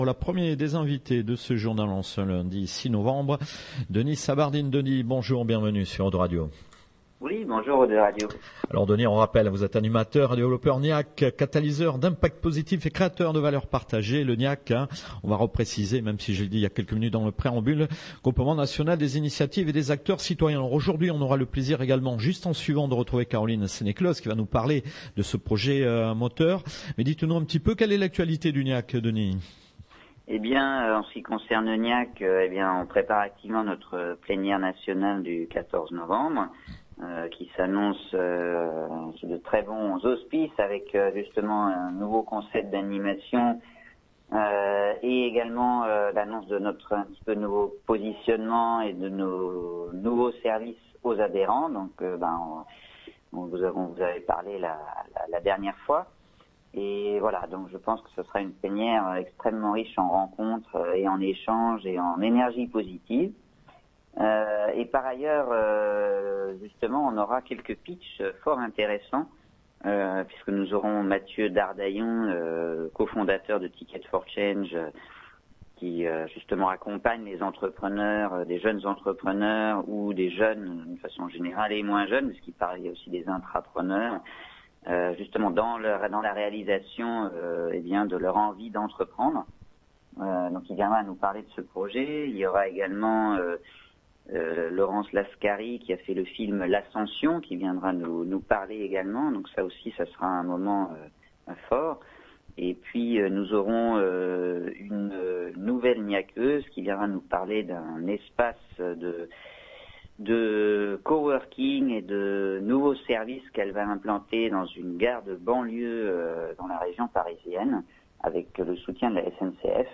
08/11/2017 : Interview GNIAC / 02 Radio : projet